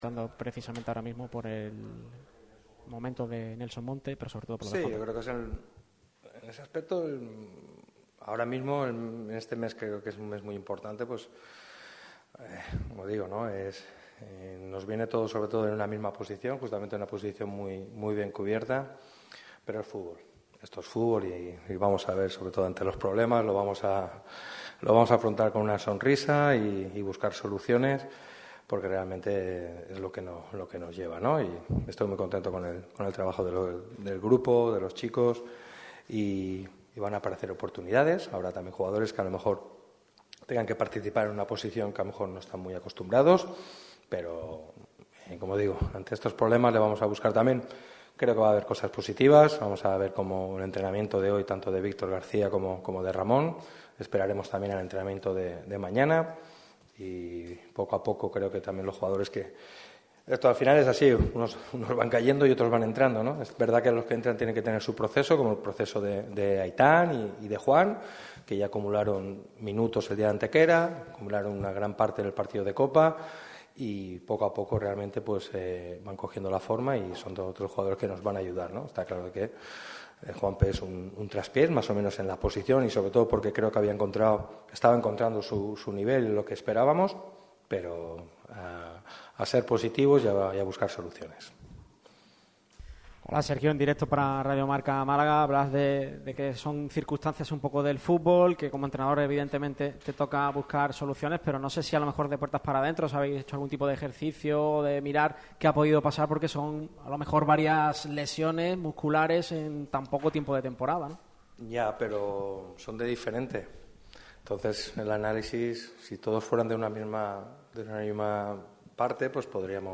Este viernes compareció en rueda de prensa Sergio Pellicer. El técnico malaguista habló en la previa del Málaga-Córdoba.